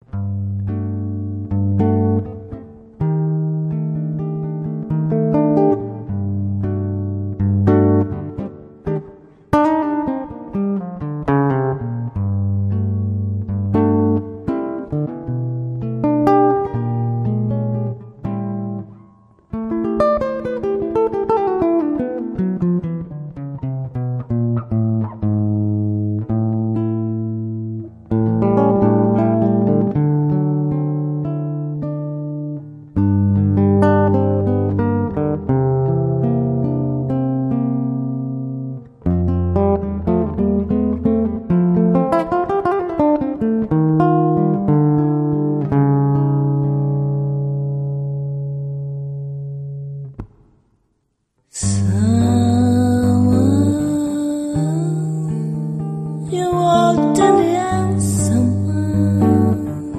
chitarra
voce